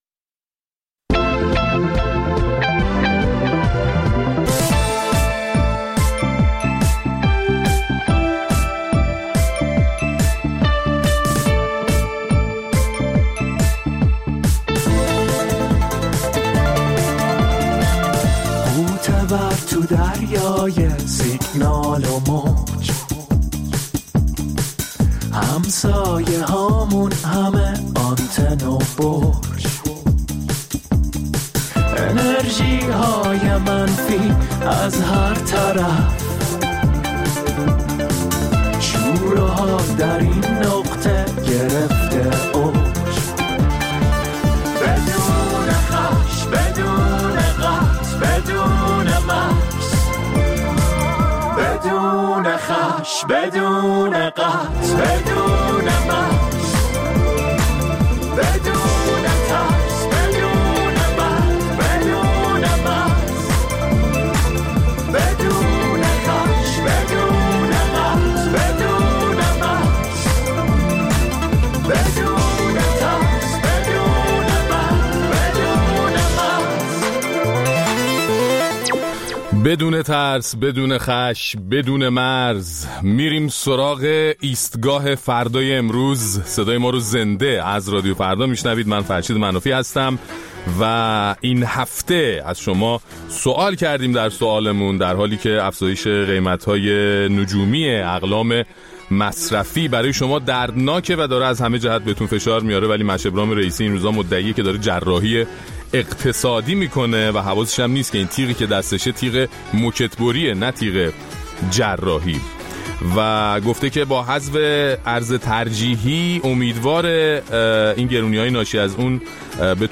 در این برنامه ادامه نظرات شما را در مورد جراحی اقتصادی و اعتراض‌های مردمی به گرانی‌ها می‌شنویم و در طول برنامه به طور مفصل به اعتراضات صورت گرفته در شهرهای مختلف ایران می‌پردازیم.